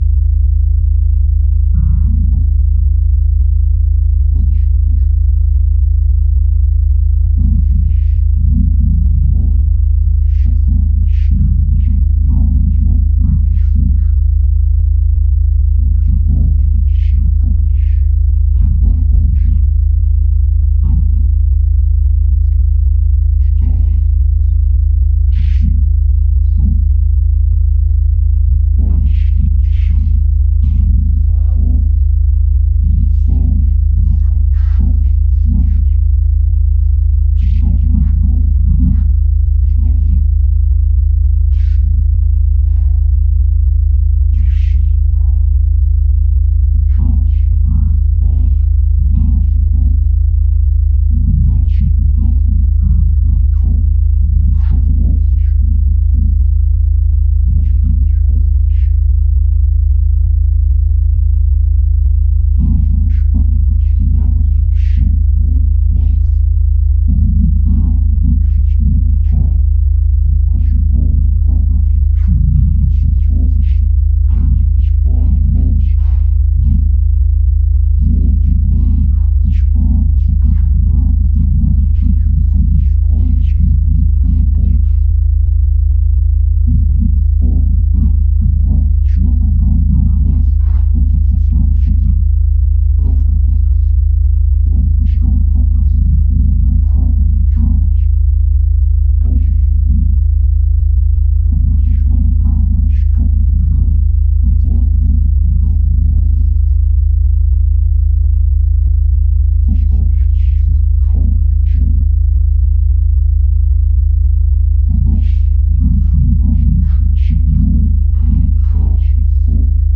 I pulled up Audacity and generated two tones:  one at 47 Hertz and one at, I think, 73 Hertz, and merged them.  I chose those frequencies because, since they are both prime numbers, their waveforms would not tend to overlap very much, and so their constructive/destructive interference would tend to be relatively chaotic, producing a pleasing (so to speak) deep and unsteady rumble.
Then, I recorded myself doing an impromptu recitation of Hamlet’s soliloquy****, which (of course) I know from memory.  I first lowered the pitch of that recording a bit, but not using the optional maximum quality pitch change (I didn’t want it to sound normal) after filtering out background noise and even breath sounds*****.
This produced three simultaneous recordings of the same thing, but with pitches at intervals that make it into a constant diminished chord (that’s where the third and fifth tones of a major triad are each reduced by a half step, making an eerie, haunting, somewhat dissonant chord).
Then I combined those three vocal tracks into one, put a bit of reverb on it, lowered the pitch again until it was at least close to that of my background tones, and combined them all after trying to adjust the balance to make sure that the vocal stuff was not quite clearly present against the background sound.
*****Removing these throughout a recording has a curious way of deadening it, and it’s rather unpleasant if you’re trying to produce something that sounds good, so was ideal for my pseudo-purposes.
weird-sound-and-soliloquoy.mp3